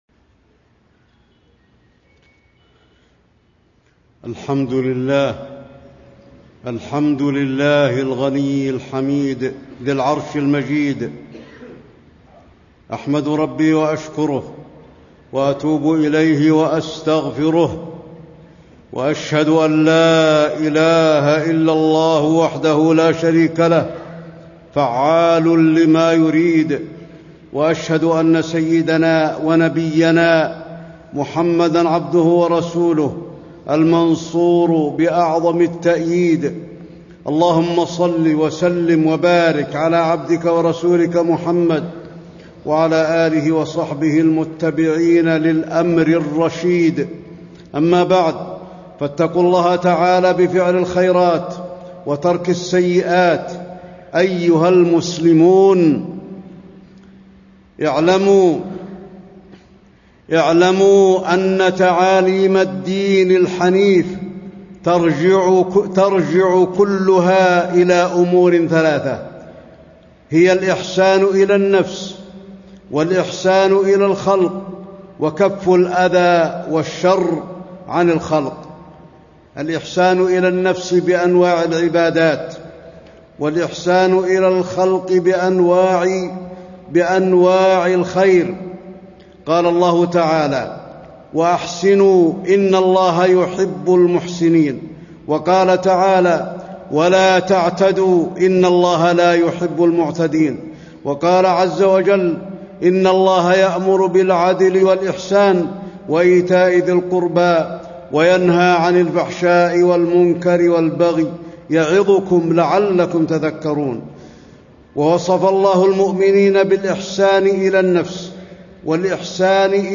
تاريخ النشر ٢٩ شعبان ١٤٣٥ هـ المكان: المسجد النبوي الشيخ: فضيلة الشيخ د. علي بن عبدالرحمن الحذيفي فضيلة الشيخ د. علي بن عبدالرحمن الحذيفي الزكاة حكم وأحكام